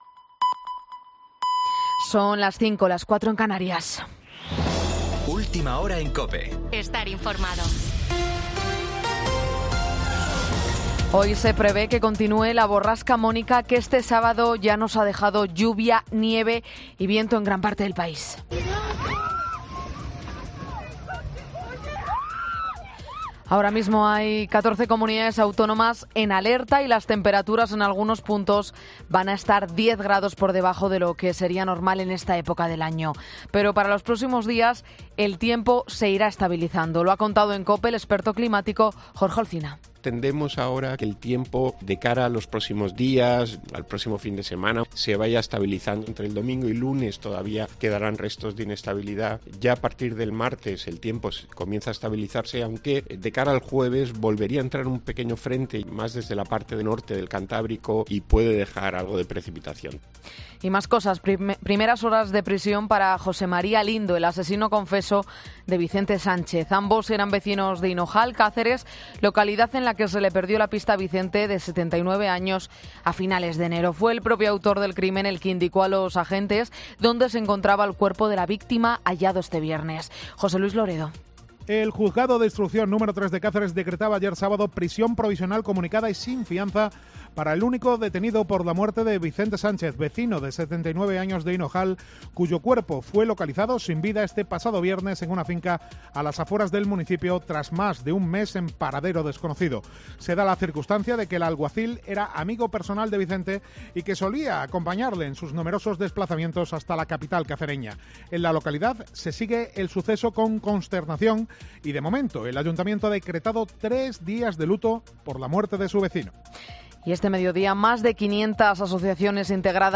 Boletín 05.00 horas del 10 de marzo de 2024